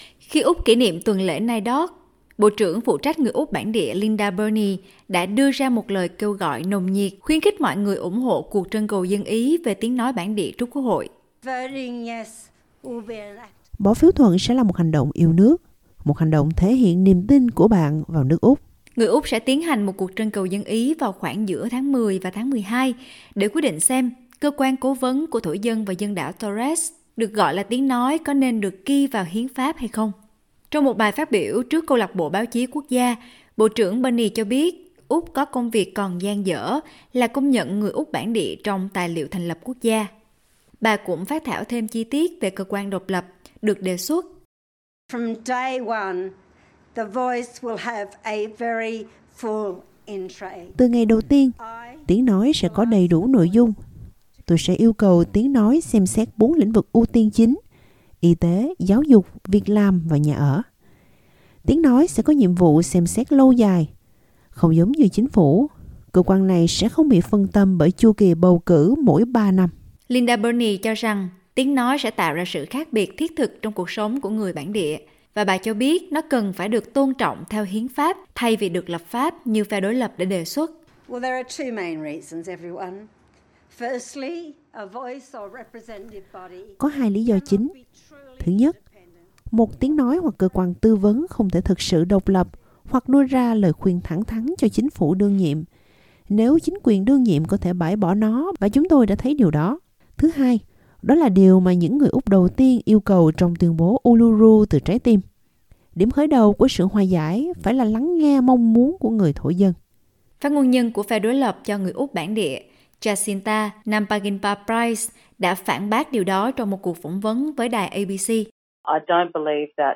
Minister for Indigenous Australians Linda Burney speaks at the National Press Club of Australia in Canberra, Wednesday, July 5, 2023.